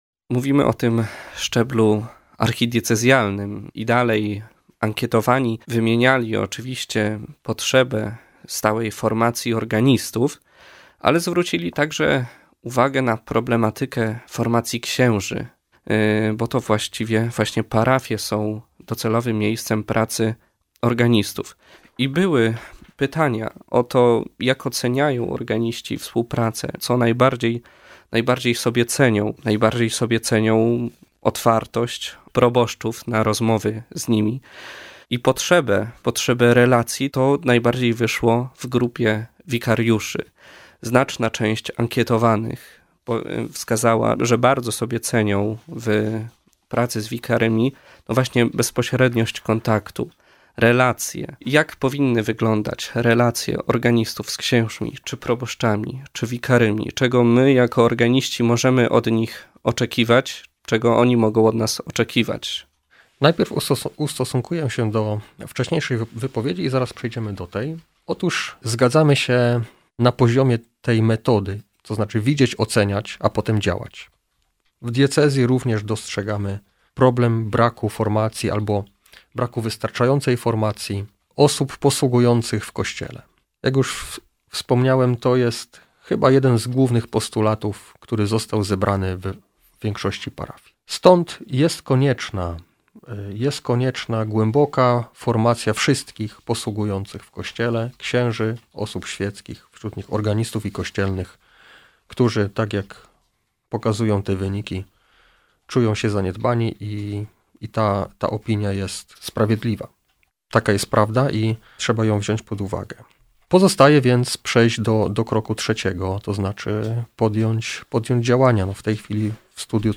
W czwartą niedzielę miesiąca zapraszamy do wysłuchania audycji z udziałem organistów. Gościem programu będzie bp Maciej Małyga, biskup pomocniczy Archidiecezji Wrocławskiej.